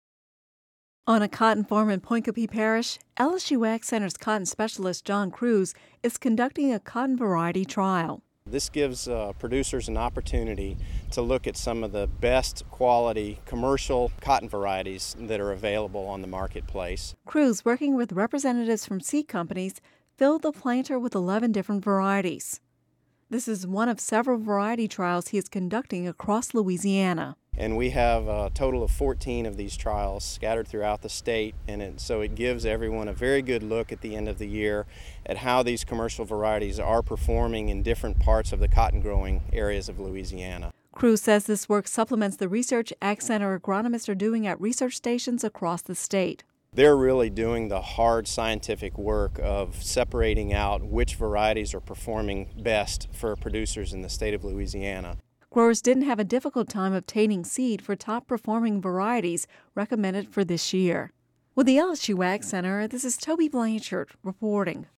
Radio News 05/03/11